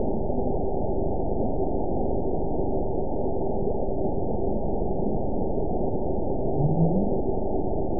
event 912379 date 03/25/22 time 17:27:41 GMT (3 years, 1 month ago) score 9.44 location TSS-AB04 detected by nrw target species NRW annotations +NRW Spectrogram: Frequency (kHz) vs. Time (s) audio not available .wav